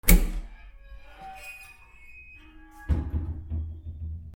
/ K｜フォーリー(開閉) / K05 ｜ドア(扉)
『チャ』